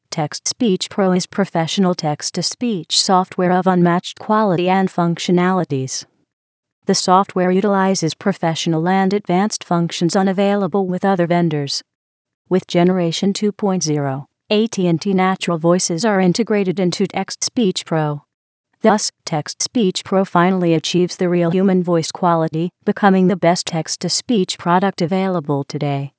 Voice Demo
AT&T Natural Voices ™ Crystal 16k (U.S. English)